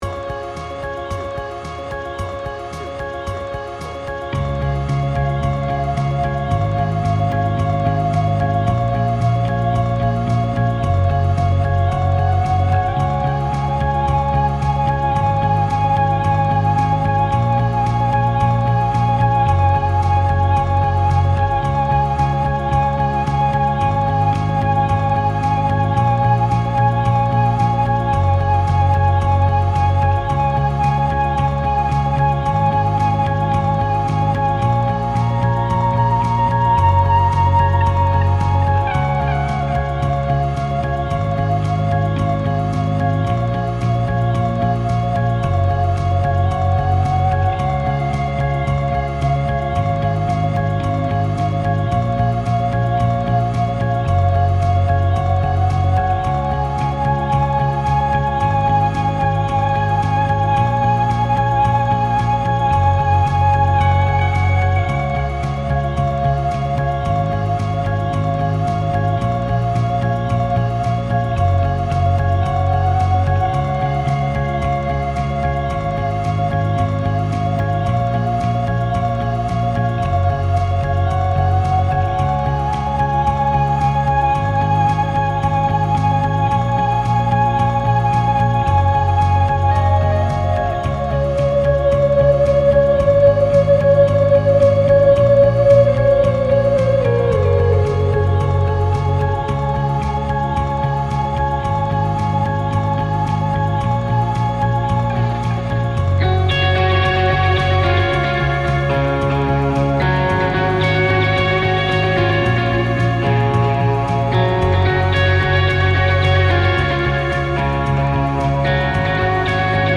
BPM : 111
Tuning : Eb
Without vocals